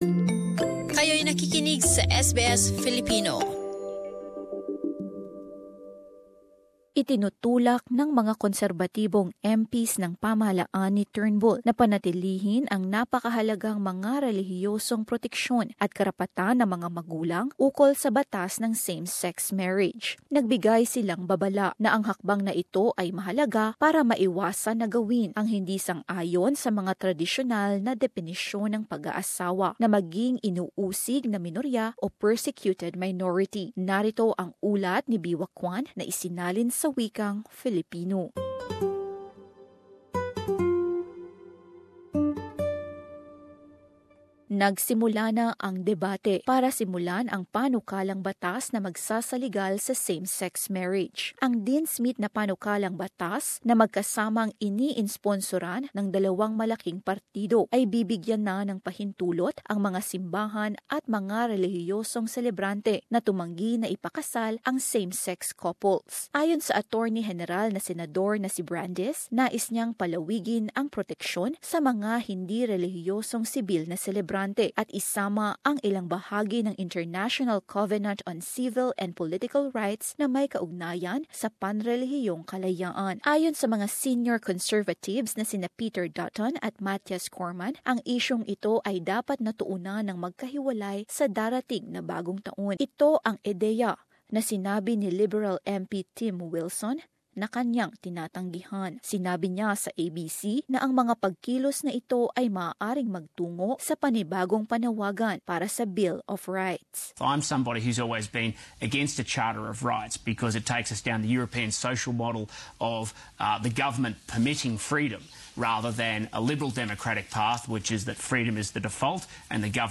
Narito ang ulat